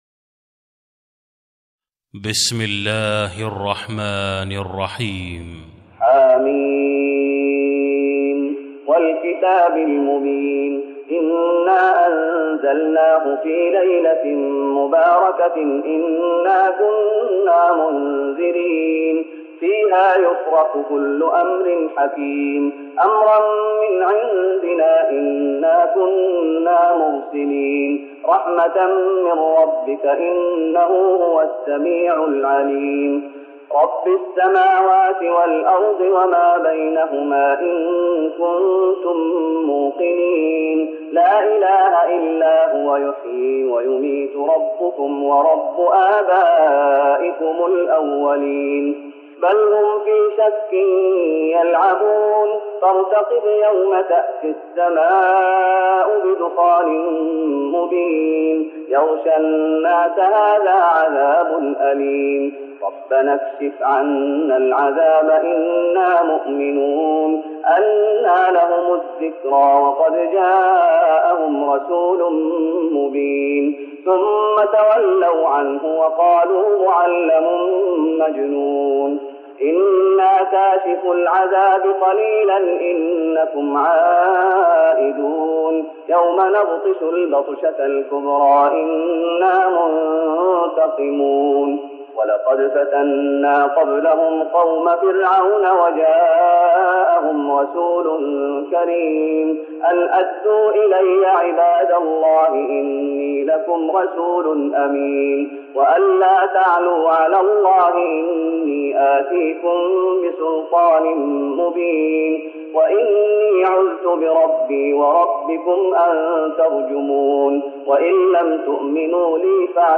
تراويح رمضان 1414هـ من سورة الدخان Taraweeh Ramadan 1414H from Surah Ad-Dukhaan > تراويح الشيخ محمد أيوب بالنبوي 1414 🕌 > التراويح - تلاوات الحرمين